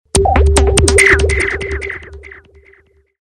Звуки рингтонов, будильников, уведомлений
Звуковое оповещение о поступлении денег на Webmoney (монетка падает на стол)